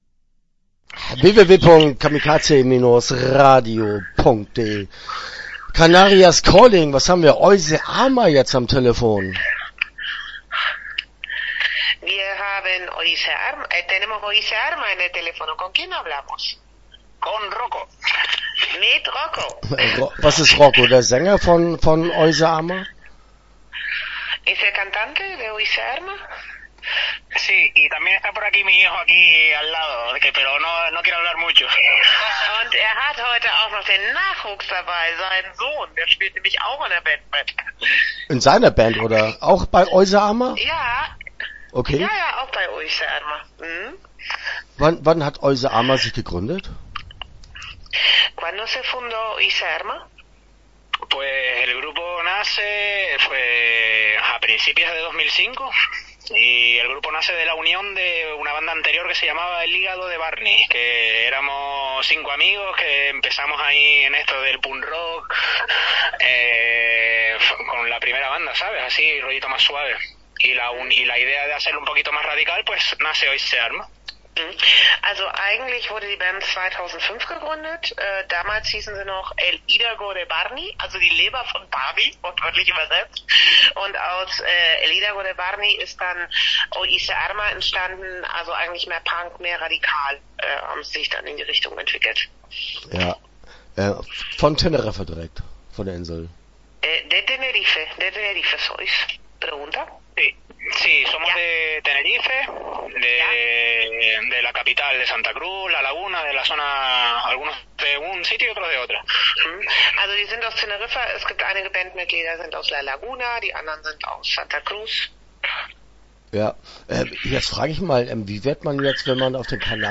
- Interview (15:57)